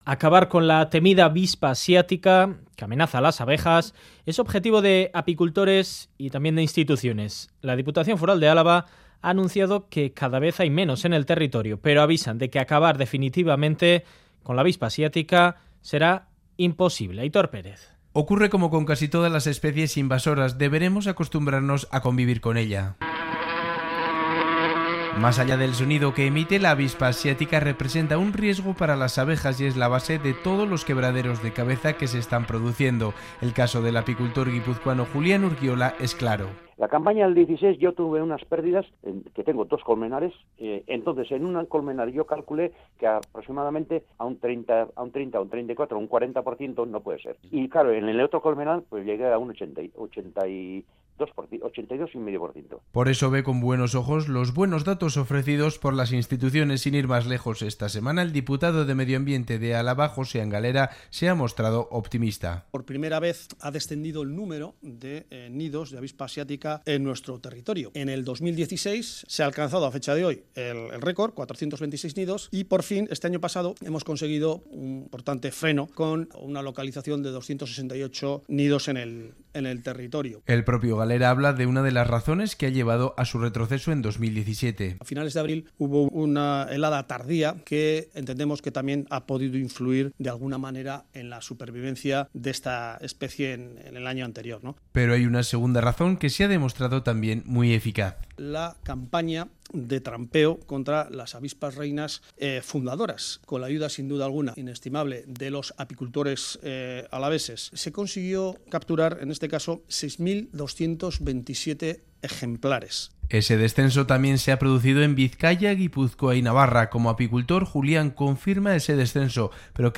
Radio Euskadi REPORTAJES La población de avispa asiática desciende por primera vez desde 2012 Última actualización: 22/02/2018 11:28 (UTC+1) Los daños que ocasionan a la abeja han puesto en alerta a las instituciones y a los apicultores, y ahora parece que las medidas puestas en marcha dan resultado.